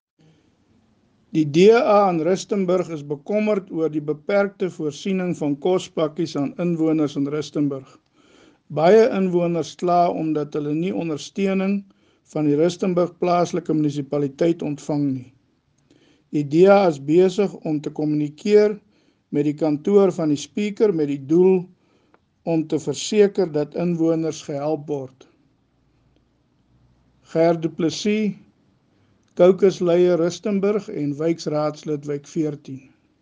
Note to Editors: Please find the attached soundbite in English and
Afrikaans from Cllr Gert Du Plessis, the DA Caucus Leader in Rustenburg Local Municipality